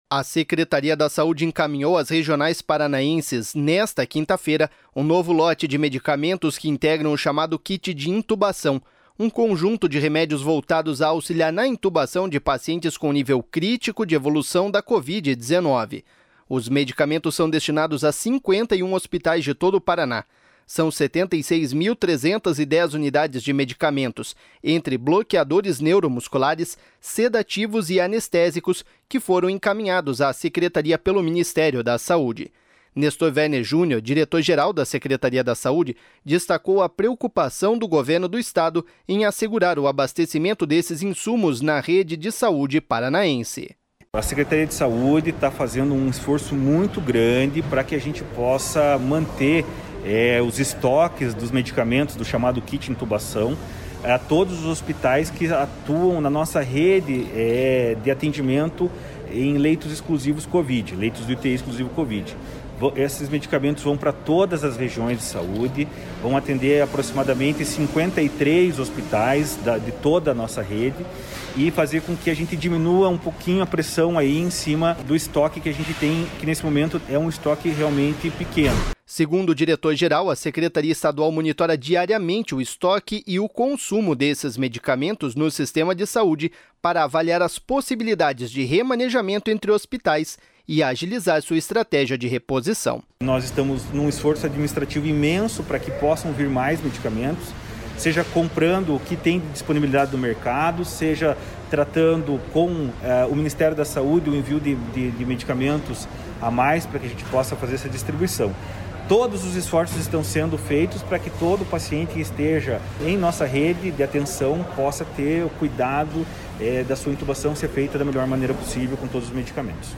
Nestor Werner Junior, diretor-geral da Secretaria de Saúde, destacou a preocupação do Governo do Estado em assegurar o abastecimento destes insumos na rede de saúde paranaense.// SONORA NESTOR WERNER JUNIOR.//